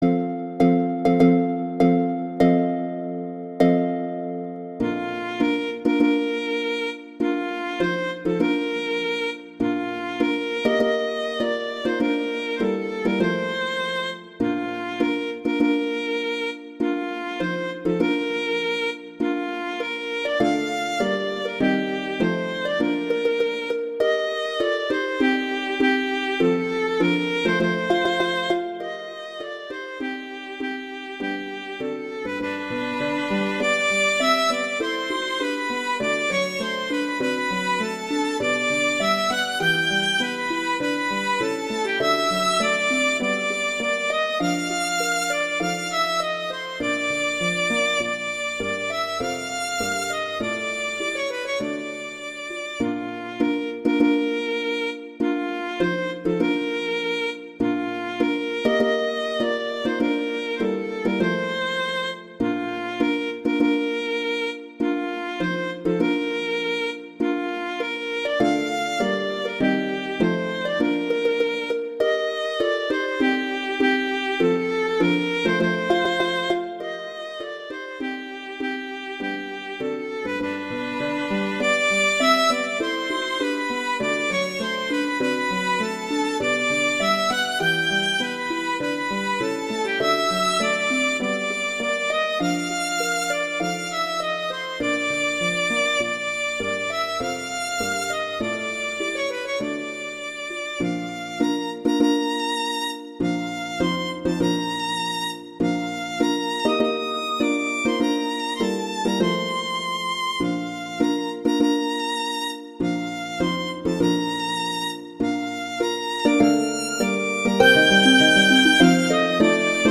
arrangements for violin and harp
wedding, traditional, classical, festival, love, french